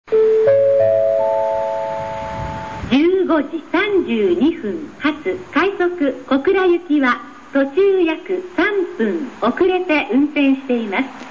2001年春に収録
スピーカー： カンノ製作所
音質：D
２番のりば 延着放送 快速・小倉 (55KB/11秒)
他の駅と異なるのは「早口」で「うるさい」です。